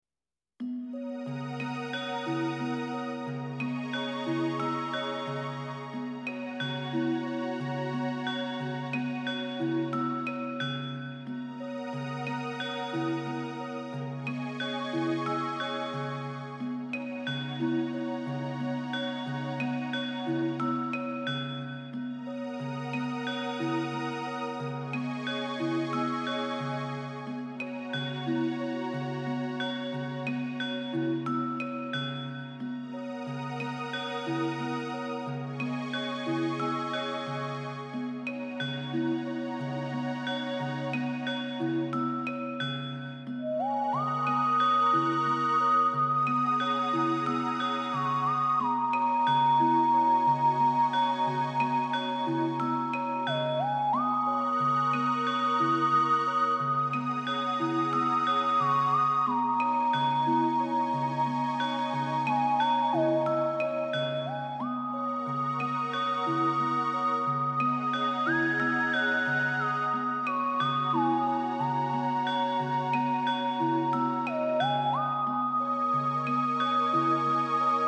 其配乐精湛若斯、音符跌宕、随着历史叙述的起伏不断变换着节奏，低沉的打击乐仿佛敲响了永乐朝的大钟